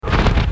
choc tombe
B_NEIGE.mp3